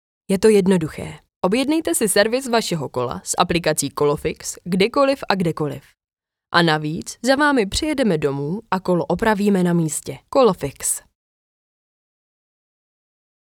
Ženský voiceover do reklamy / 90 sekund
Hledáte do svého videa příjemný ženský hlas?
Nahrávání probíhá v profesionálním studiu a výsledkem je masterovaná audio stopa ve formátu WAV, ořezaná o nádechy a další rušivé zvuky.